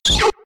Cri de Rondoudou K.O. dans Pokémon X et Y.